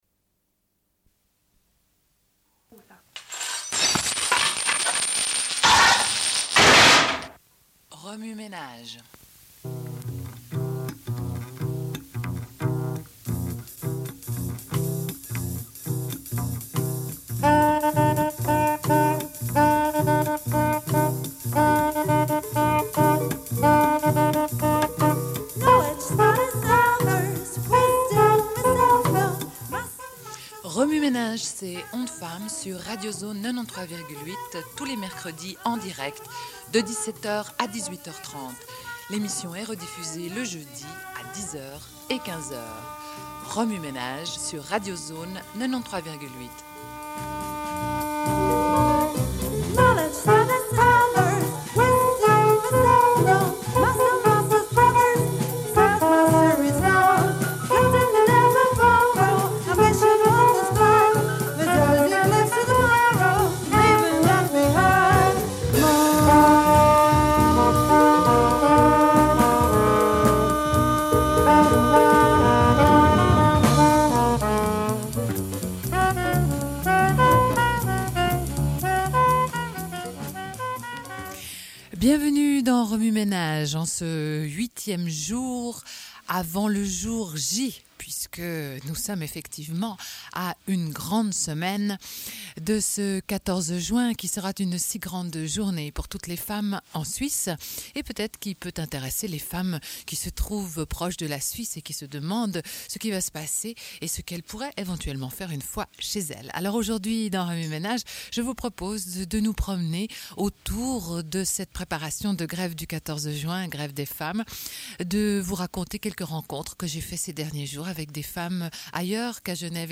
Une cassette audio, face A00:31:28